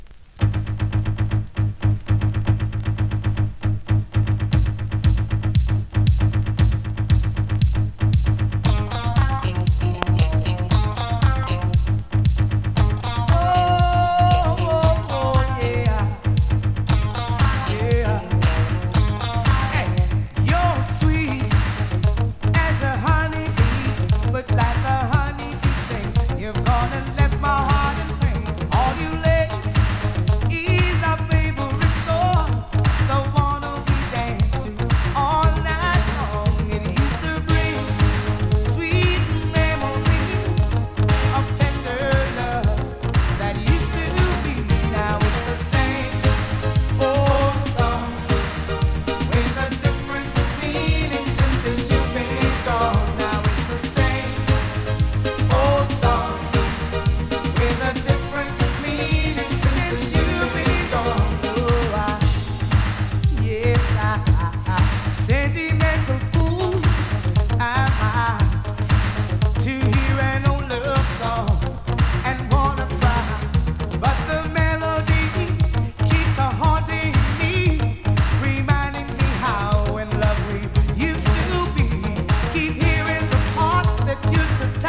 コメントレアJAMAICAプレス!!
スリキズ、ノイズ比較的少なめで